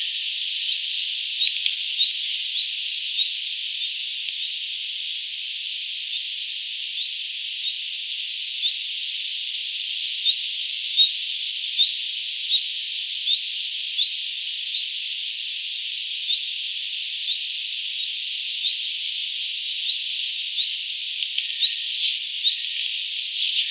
vit-tiltaltti lähempää